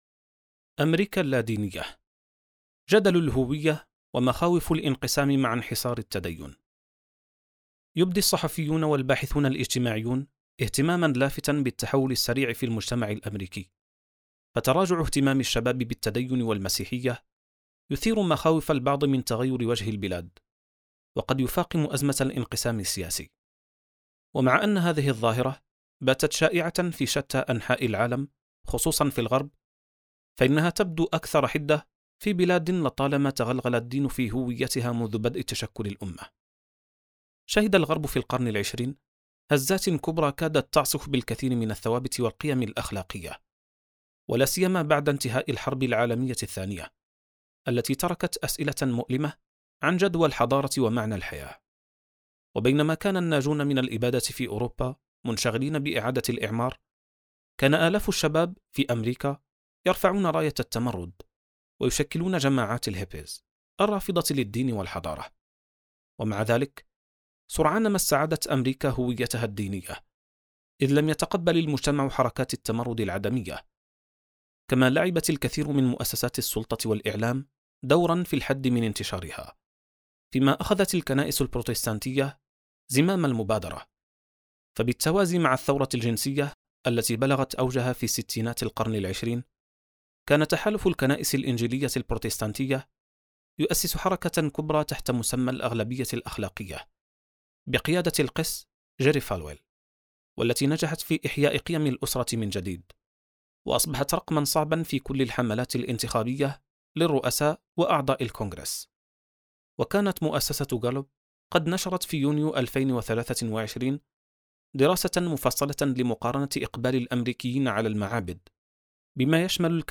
كتاب صوتي | خارطة الطوائف (1319): أميركا اللادينية • السبيل